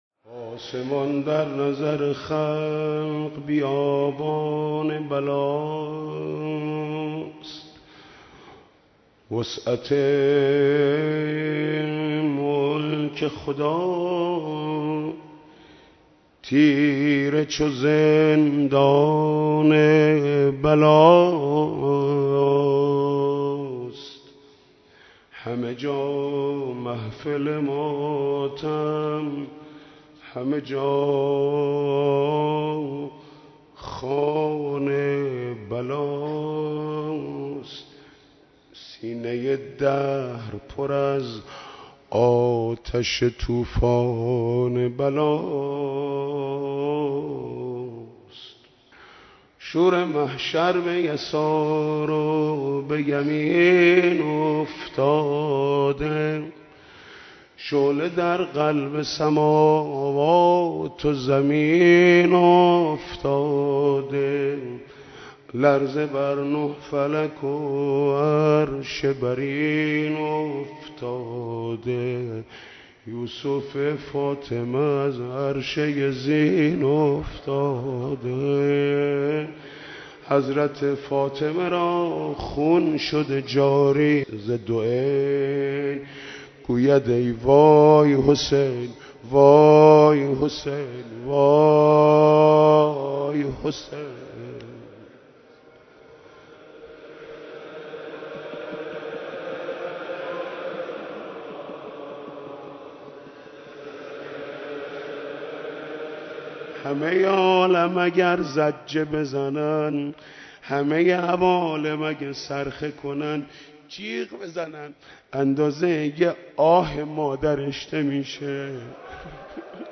مراسم عزاداری شام غریبان برگزار شد